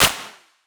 KC - Dre Snare.wav